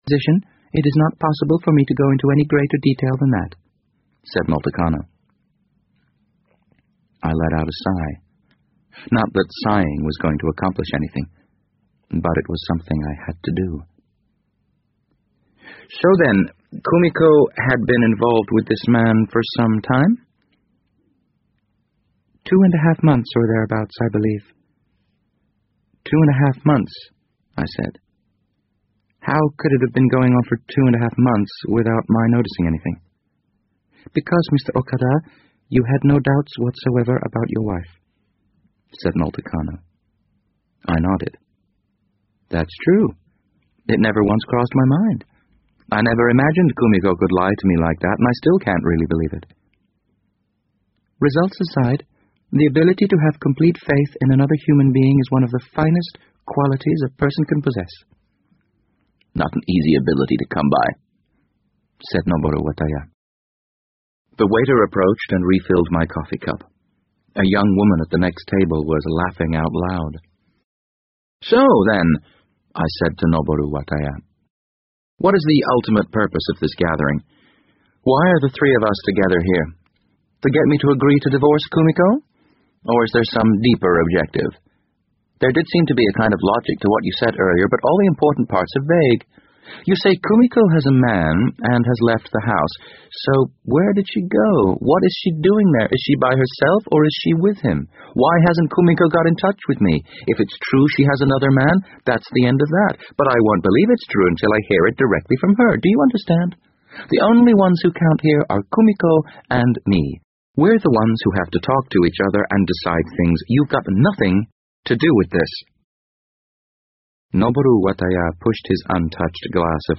BBC英文广播剧在线听 The Wind Up Bird 005 - 20 听力文件下载—在线英语听力室